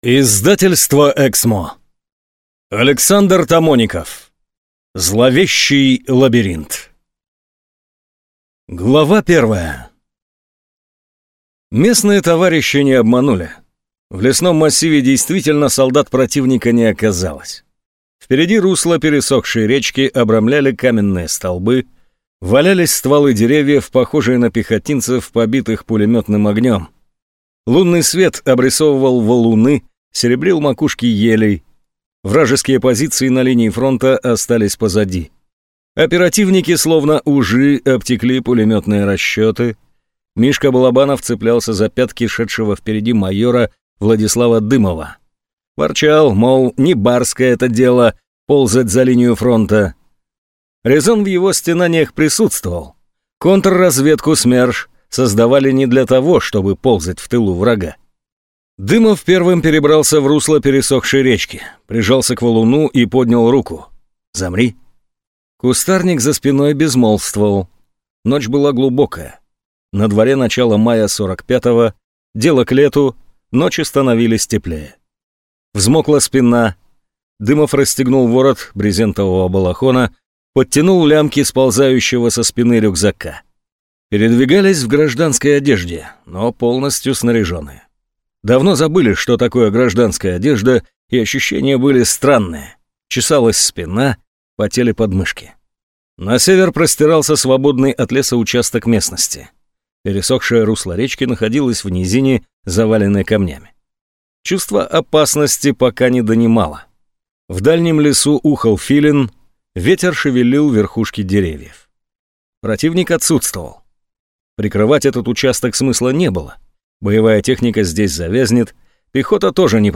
Аудиокнига Зловещий лабиринт | Библиотека аудиокниг